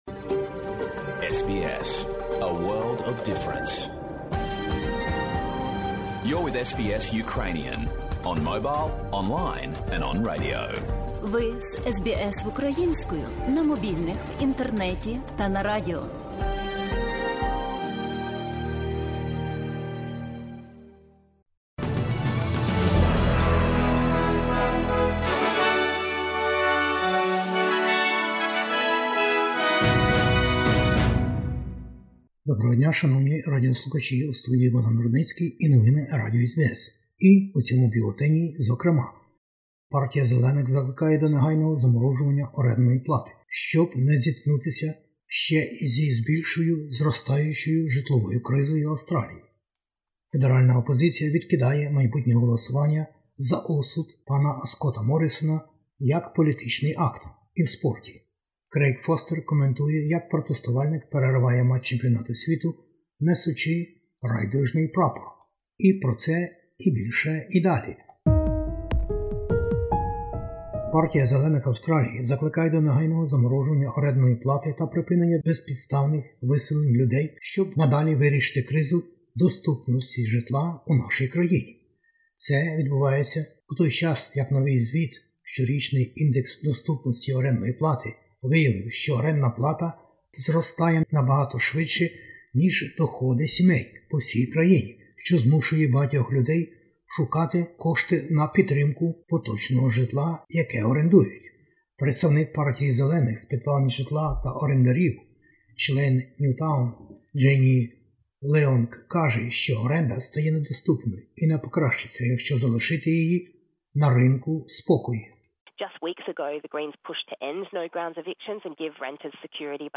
SBS News in Ukrainian - 29/11/2022